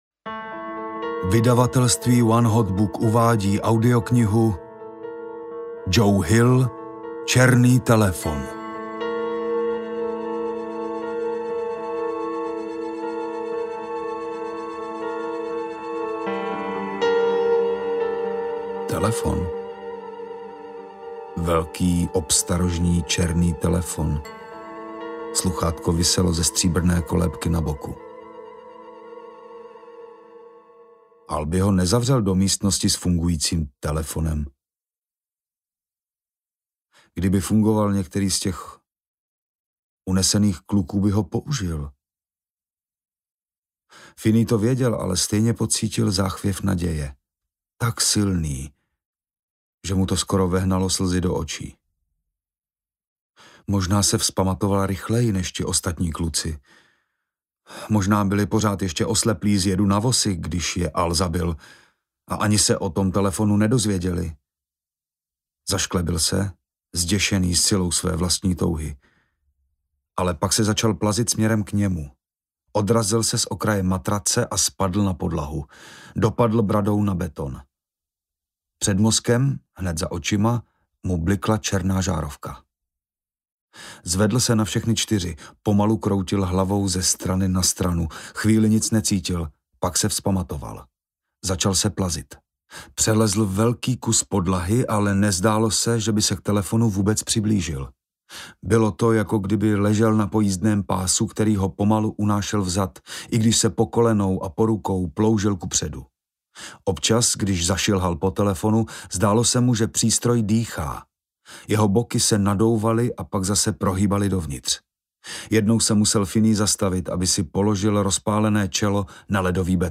Černý telefon a další příběhy audiokniha
Ukázka z knihy